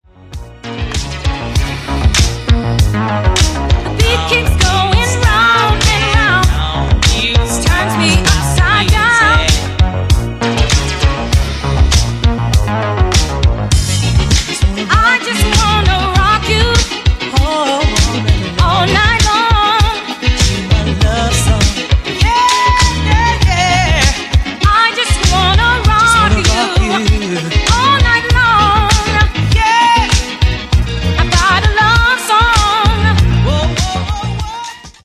Genere:   Pop | Rock | Dance